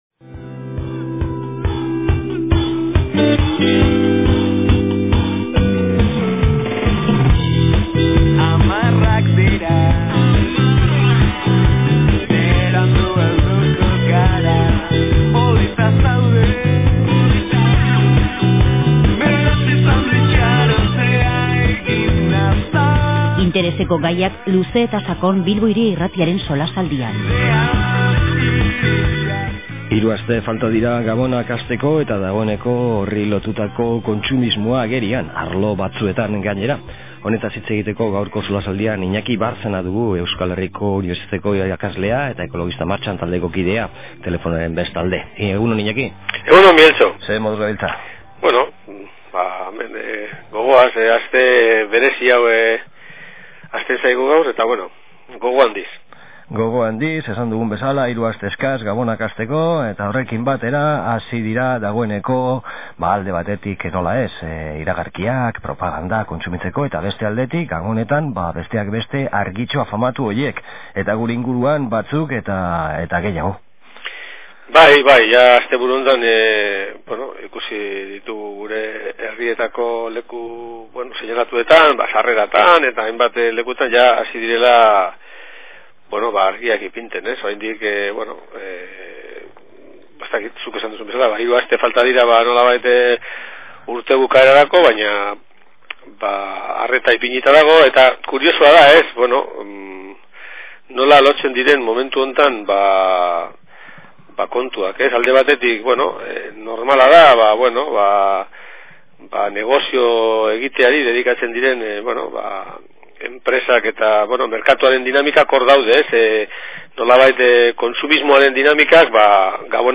SOLASALDIA: Gabonetako kontsumo energetikoa